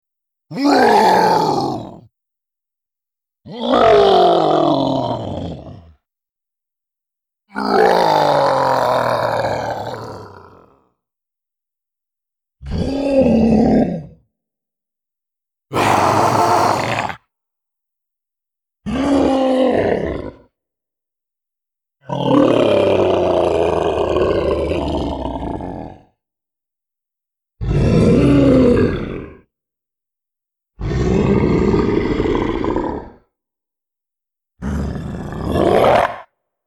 Download Creature sound effect for free.
Creature